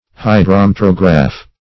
Search Result for " hydrometrograph" : The Collaborative International Dictionary of English v.0.48: Hydrometrograph \Hy`dro*met"ro*graph\, n. [Hydro-, 1 + Gr.
hydrometrograph.mp3